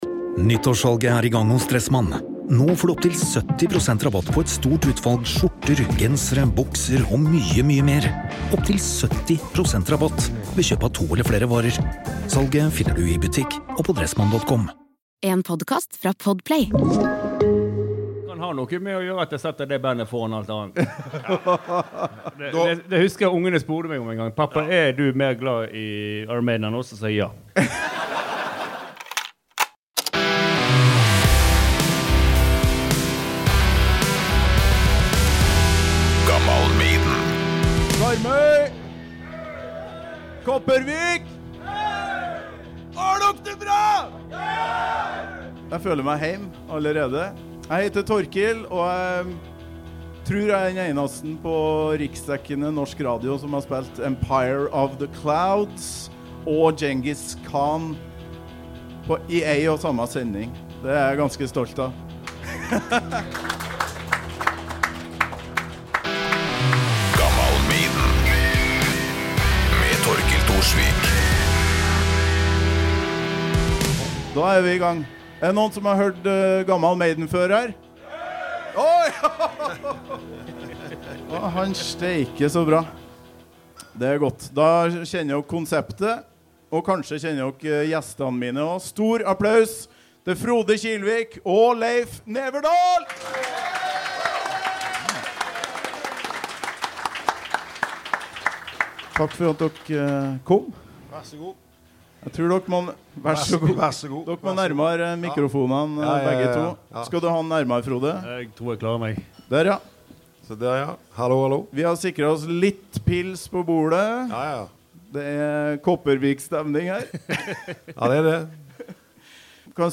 Stinn brakke på vakre Karmøy.
Gammal Maiden var ikke vond å be da folkene bak Karmøygeddon og Beyond The Gates satte seg fore å lage en liten Iron Maiden-festival i Kopervik. Topp stemning, fullstappa bar, tre mikrofoner og herlige folk. Kjenn på stemingen i alt du får høre her, for dette er verdens beste fans samla på ett sted.